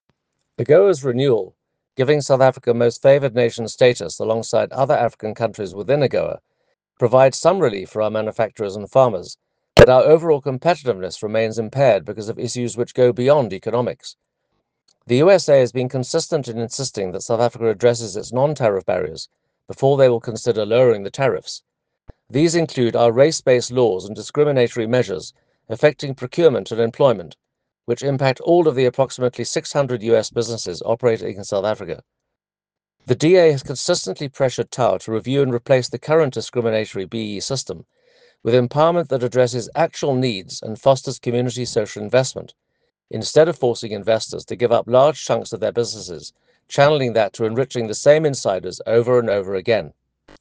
soundbite by Toby Chance MP.